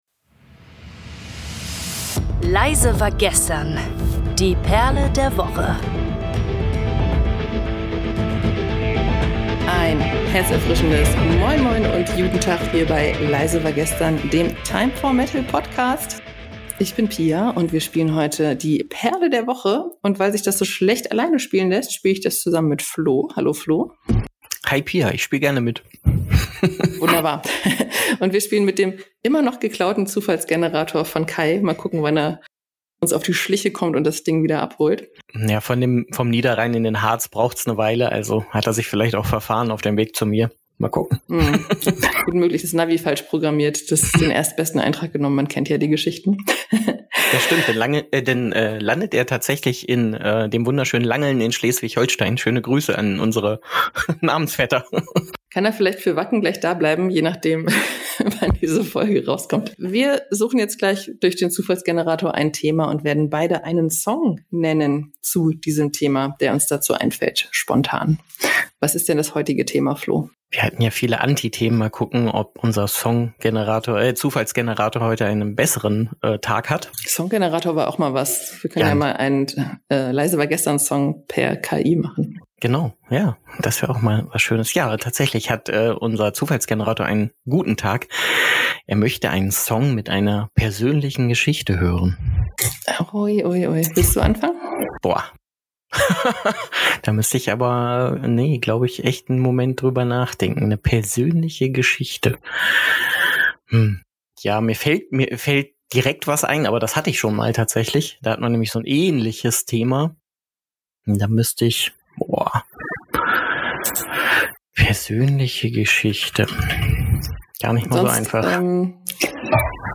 Moderation: